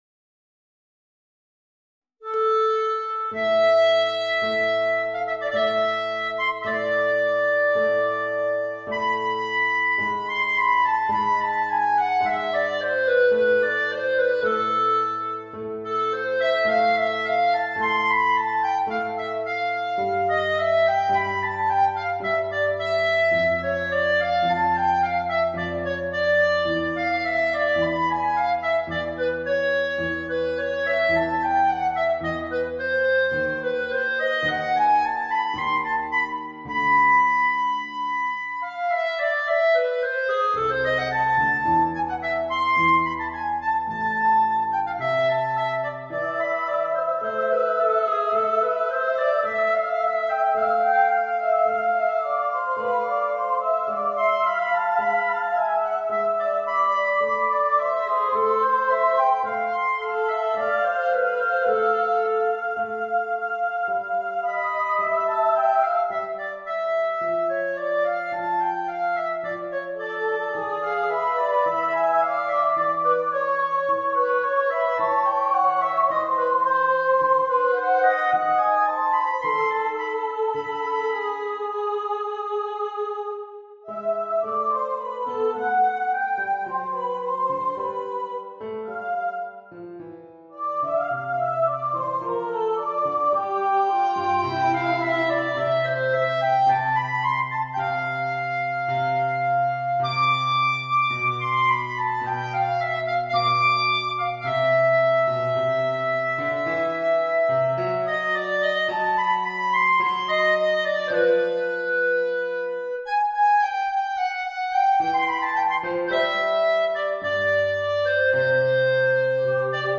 Voicing: Soprano, Clarinet and Piano